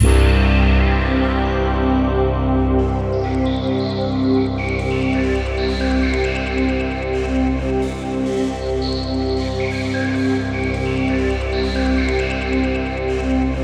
BRASSPADC2-L.wav